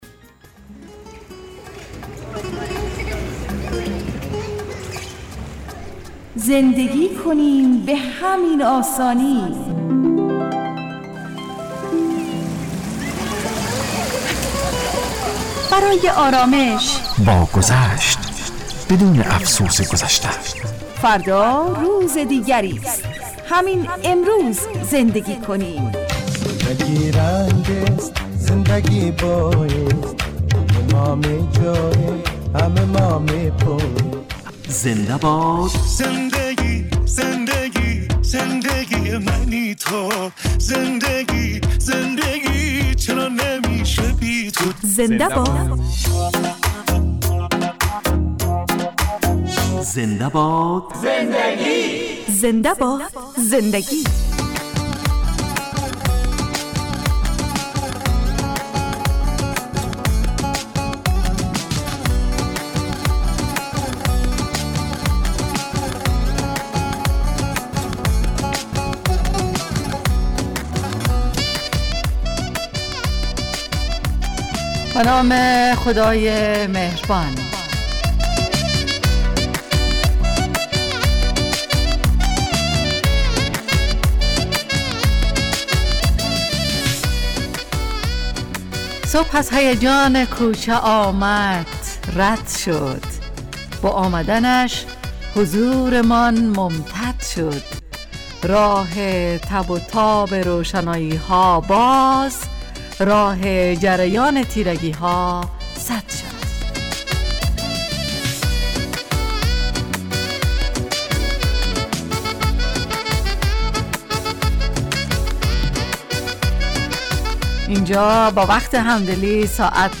زنده باد زندگی(گپ و سخن)